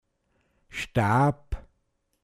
Pinzgauer Mundart Lexikon
Details zum Wort: Staab. Mundart Begriff für Staub